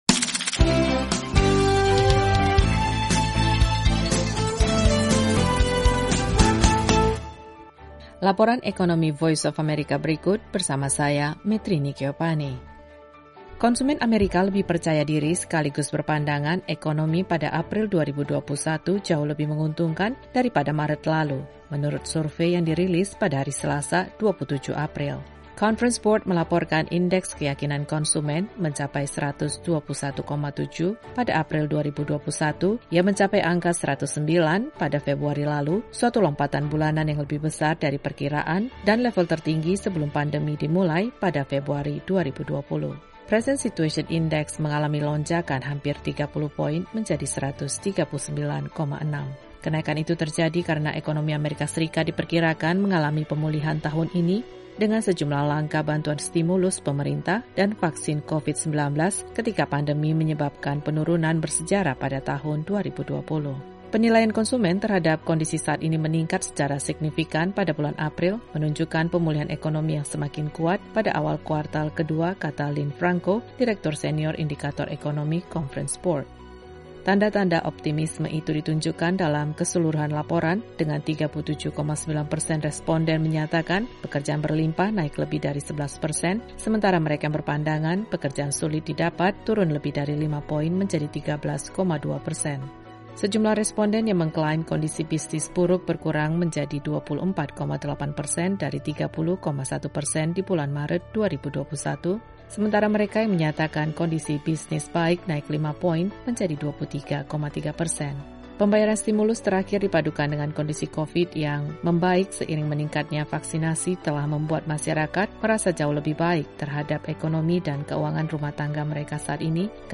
Laporan Ekonomi VOA mengetengahkan hasil survei yang menyatakan kepercayaan konsumen AS mencapai angka tertinggi pasca pandemi. Simak juga laporan terkait Jerman yang bekerja 'keras dan segera' mengirim bantuan ke India.